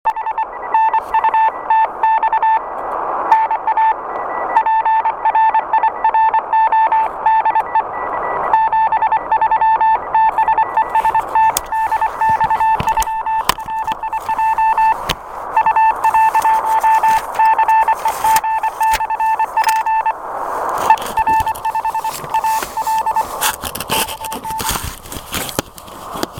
Кому интересно, представляю сообщение о участии в "Весеннем полевом дне".
И немного аудио, приём на Р-143.